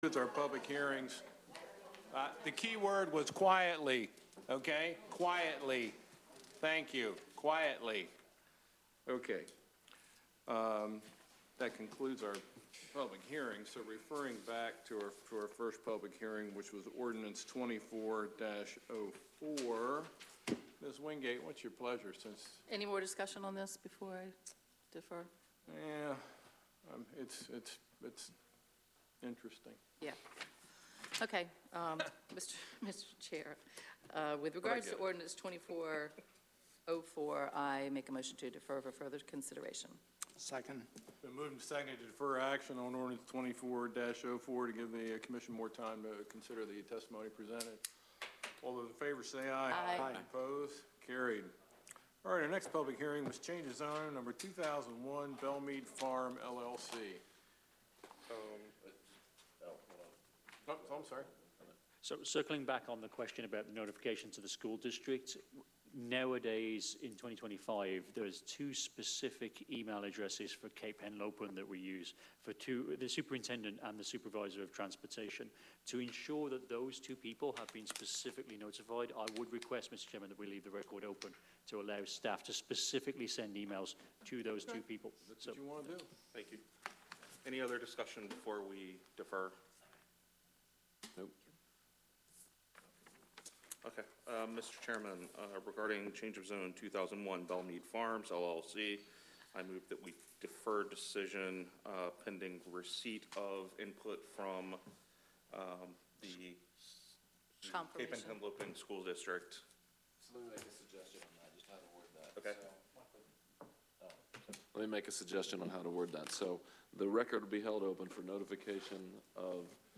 Planning & Zoning Meeting
Council Chambers, Sussex County Administrative Office Building, 2 The Circle, Georgetown